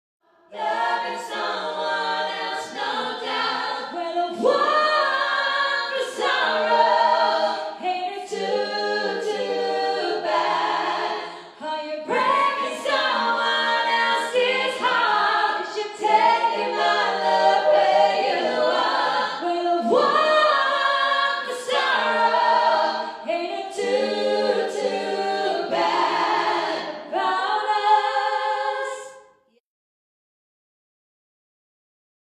• Outstanding Vocals & Harmonies